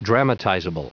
Prononciation du mot dramatizable en anglais (fichier audio)
Prononciation du mot : dramatizable